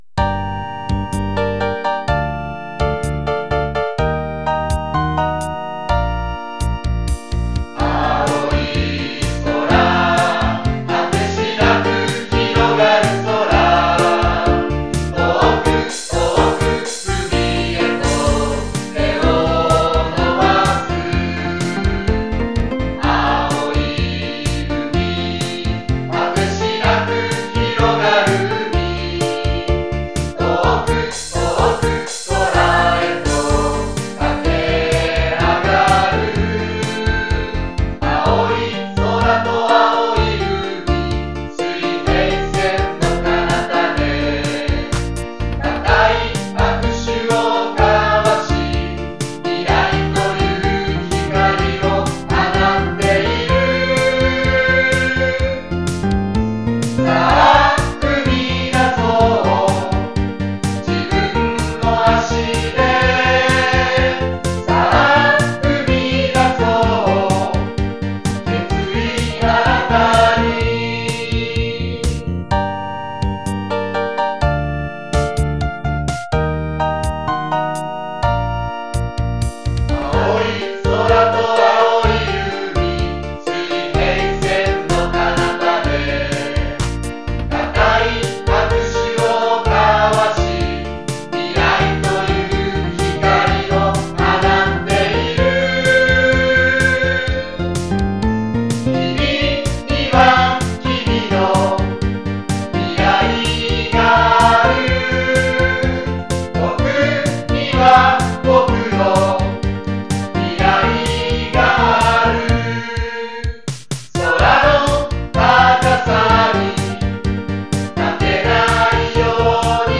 校歌　　ピアノ伴奏、